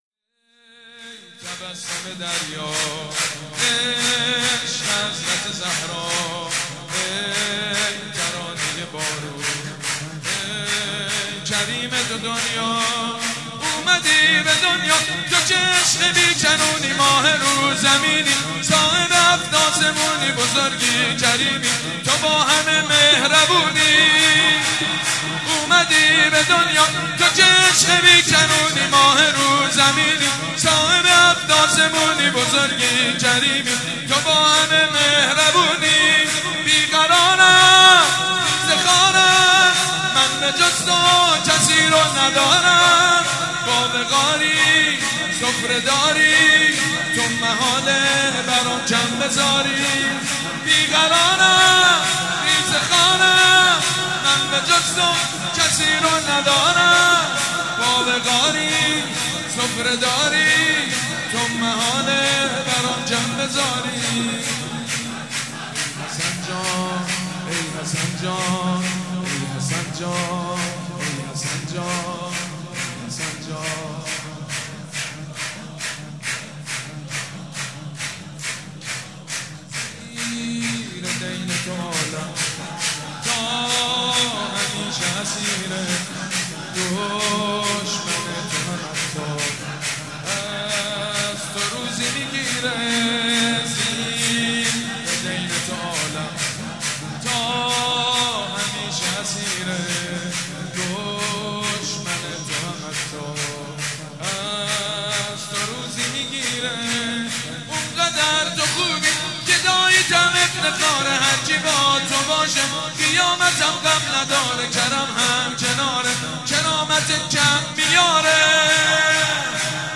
حاج سيد مجید بنی فاطمه
ولادت امام حسن (ع)